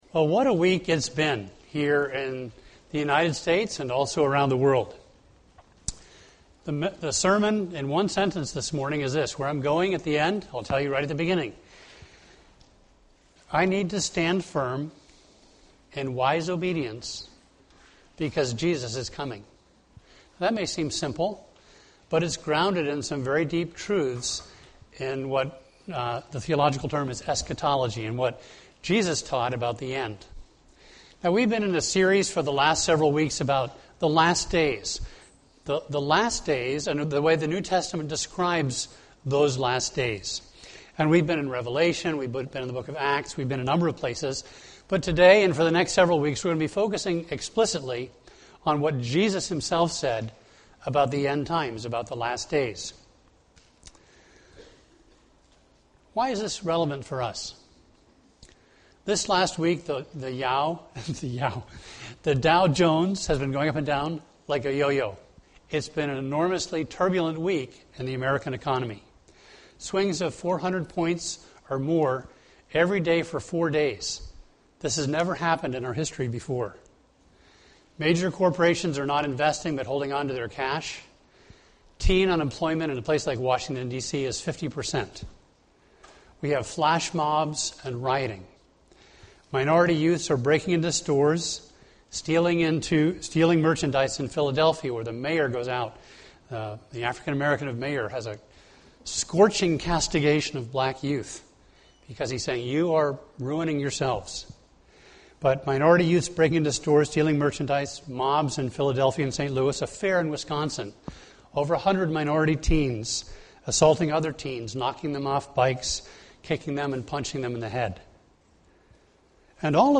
A message from the series "End Times."